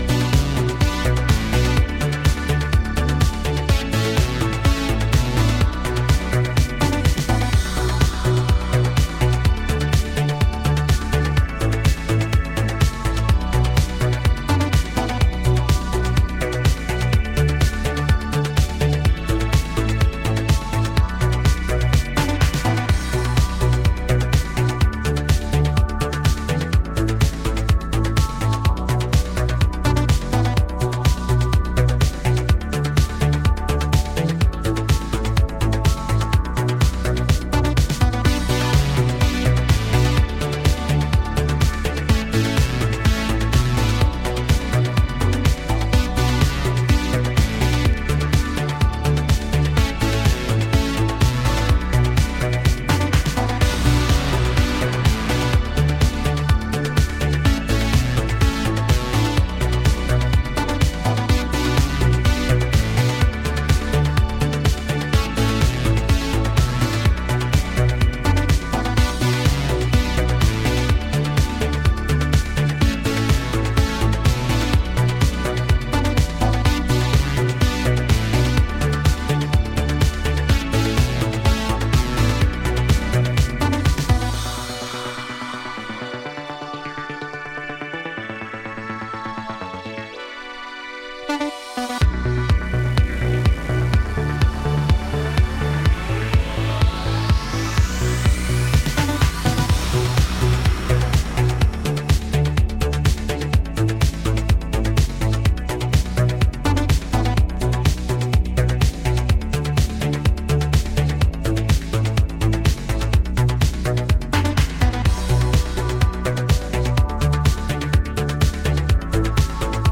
そして、各曲インストver.が非常にナイスなので、シンセ・ディスコ好きも要チェックです！